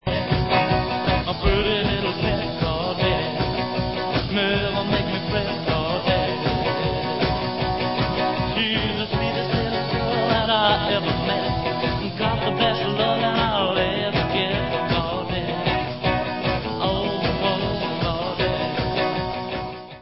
vocal/group demo